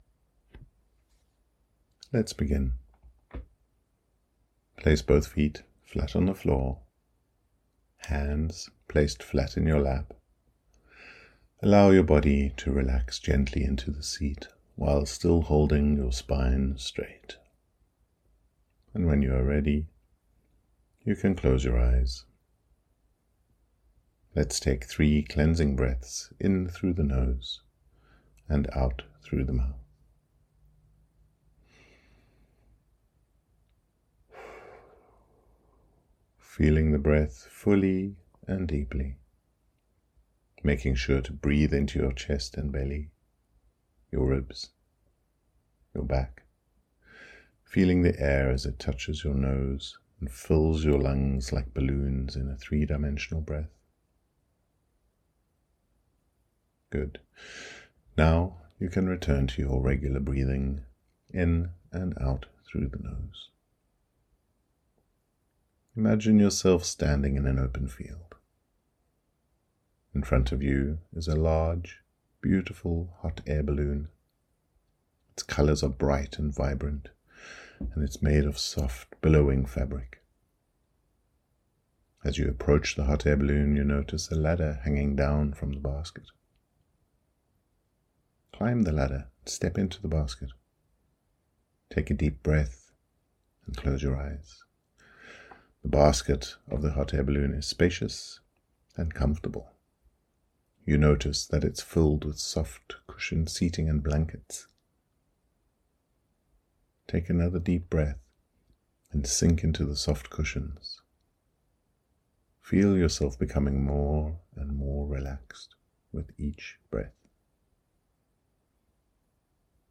float Away Meditation
WS08-meditation-float-away.mp3